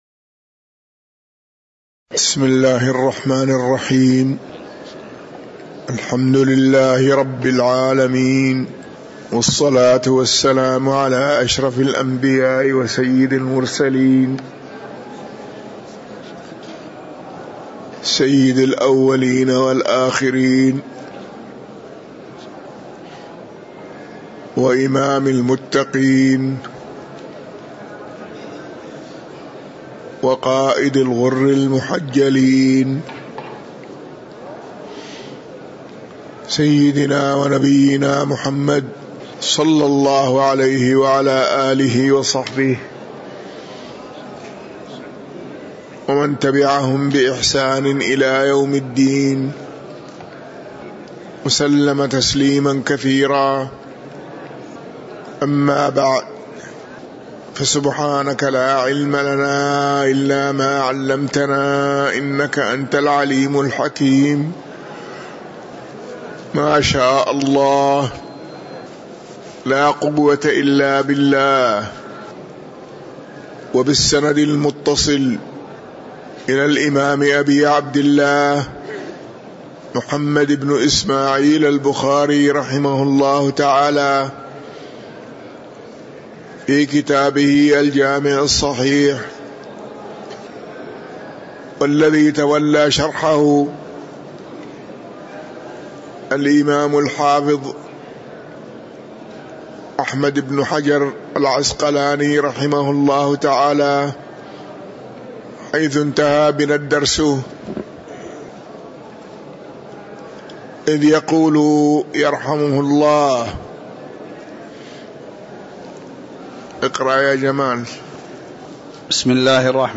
تاريخ النشر ٣ جمادى الأولى ١٤٤٣ هـ المكان: المسجد النبوي الشيخ